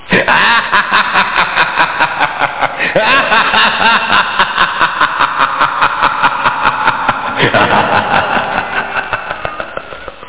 home *** CD-ROM | disk | FTP | other *** search / AMOS PD CD / amospdcd.iso / samples / thriller ( .mp3 ) < prev next > Amiga 8-bit Sampled Voice | 1989-08-27 | 86KB | 1 channel | 8,363 sample rate | 10 seconds